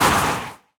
Minecraft Version Minecraft Version snapshot Latest Release | Latest Snapshot snapshot / assets / minecraft / sounds / mob / breeze / hurt2.ogg Compare With Compare With Latest Release | Latest Snapshot